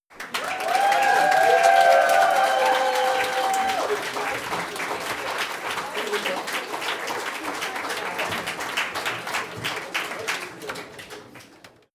HUMAN_CROWD_Applause.wav